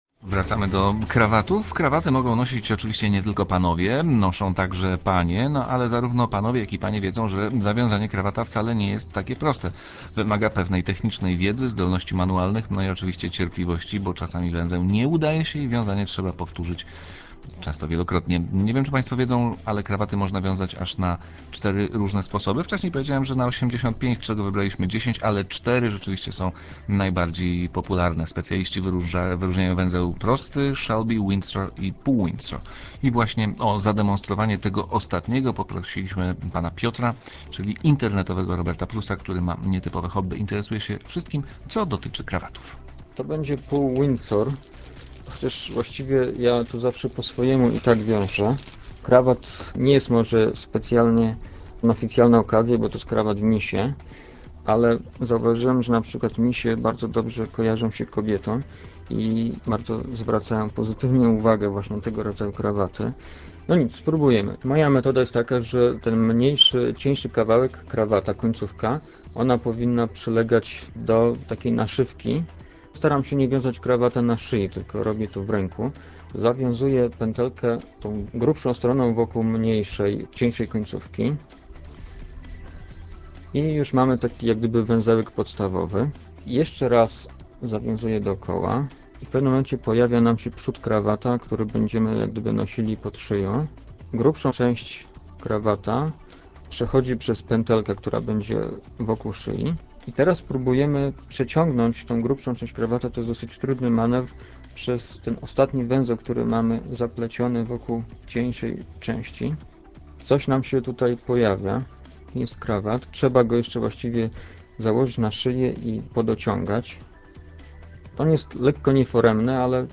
Dźwiękowy zapis Trójkowej audycji można znaleźć poniżej: